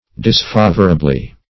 disfavorably - definition of disfavorably - synonyms, pronunciation, spelling from Free Dictionary Search Result for " disfavorably" : The Collaborative International Dictionary of English v.0.48: Disfavorably \Dis*fa"vor*a*bly\, adv.
disfavorably.mp3